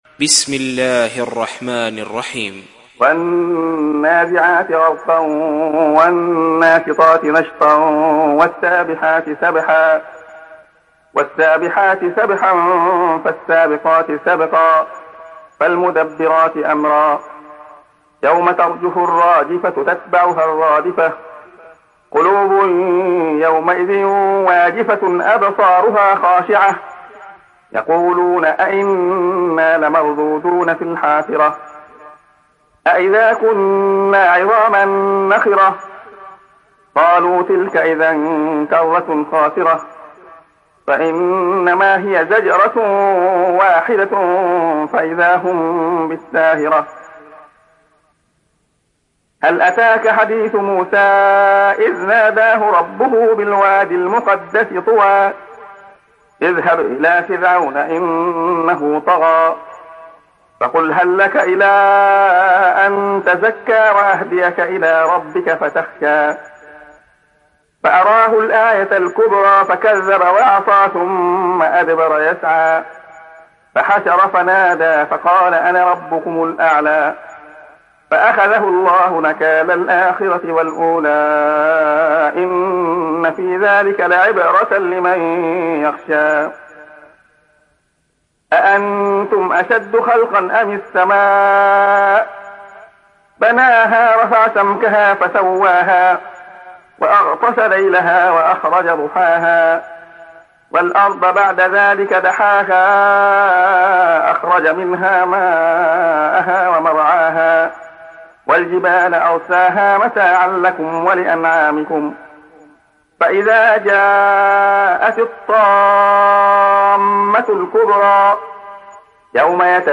دانلود سوره النازعات mp3 عبد الله خياط روایت حفص از عاصم, قرآن را دانلود کنید و گوش کن mp3 ، لینک مستقیم کامل